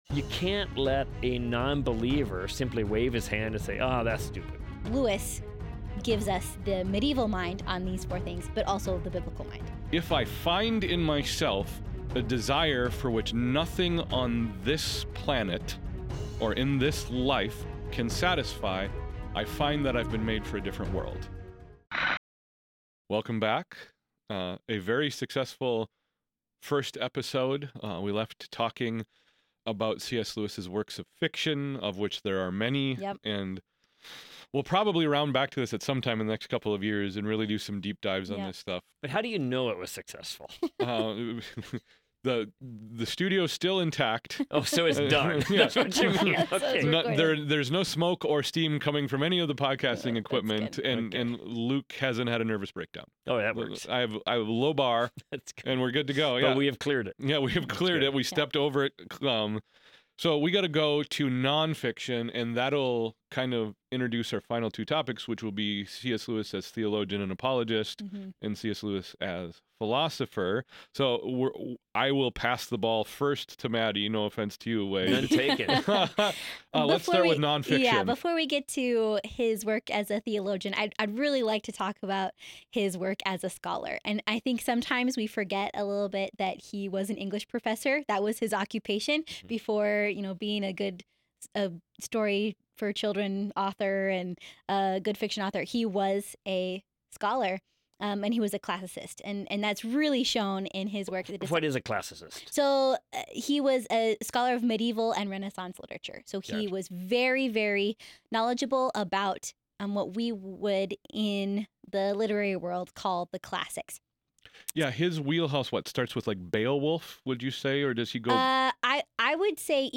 In this part 2 of our series on C.S.Lewis, our trio explores the non-fiction works and core theological arguments of C.S. Lewis. They transition from discussing his fiction to his role as a scholar, theologian, and philosopher, highlighting his unique ability to make complex topics accessible.